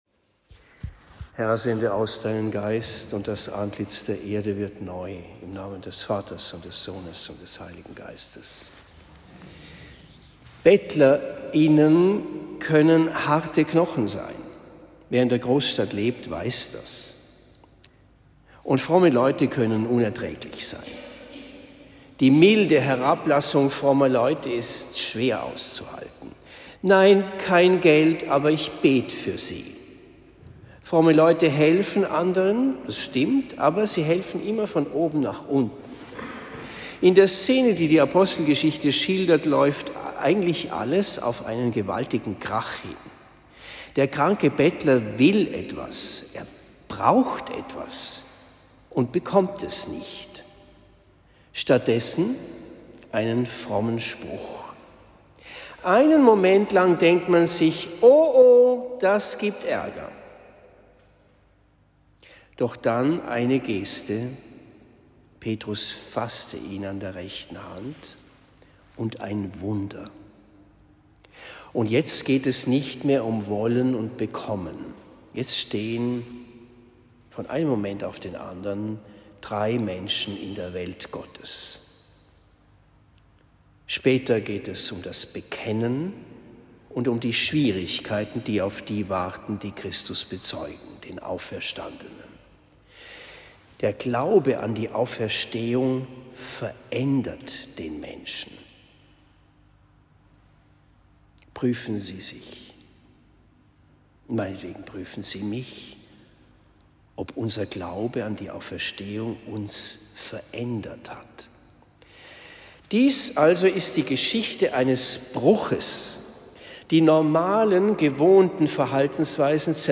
Predigt in St. Laurentius zu Marktheidenfeld am 12. April 2023